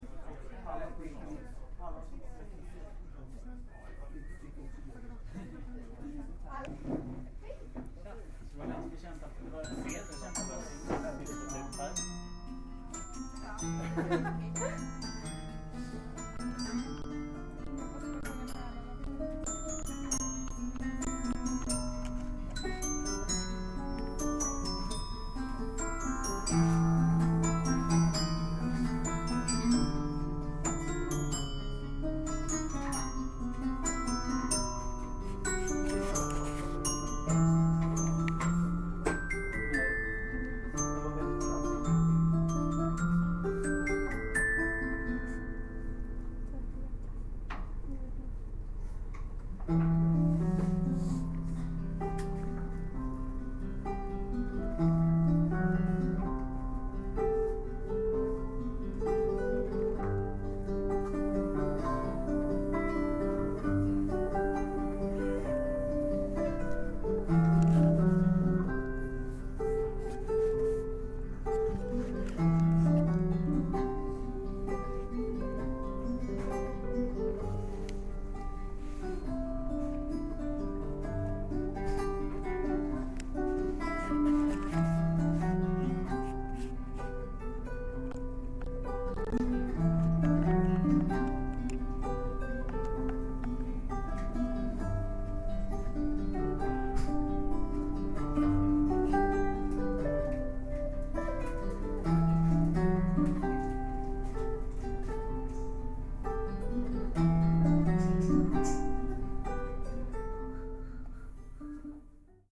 Sunshine story at "hugos" Nice performance even if one string was broken on the Fender.
sunshine_story_live.mp3